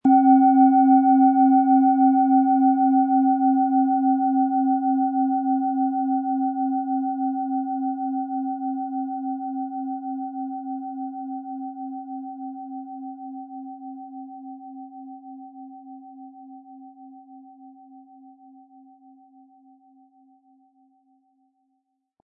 Planetenschale® Gestärkt werden & Fühle Dich wohl mit DNA-Ton, Ø 12,8 cm, 180-260 Gramm inkl. Klöppel
Planetenton 1
Sie möchten den schönen Klang dieser Schale hören? Spielen Sie bitte den Originalklang im Sound-Player - Jetzt reinhören ab.
HerstellungIn Handarbeit getrieben
MaterialBronze